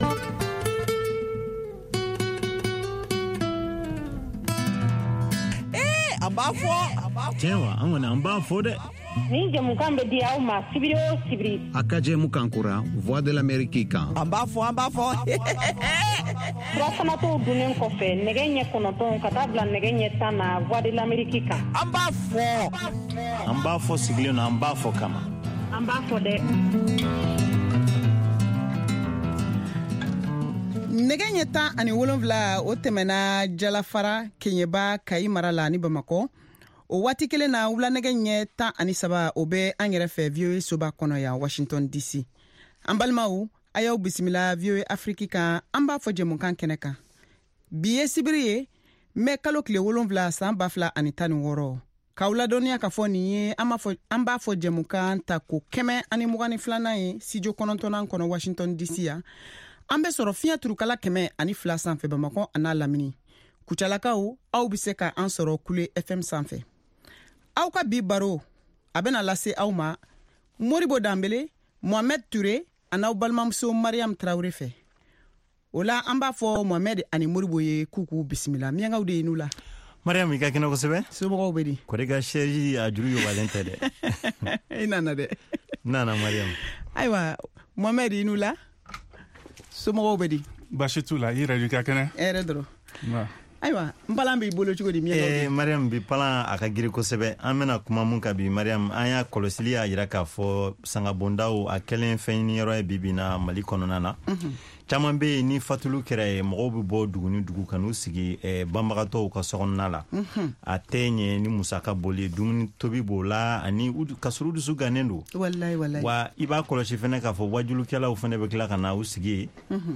Bambara Call-in Show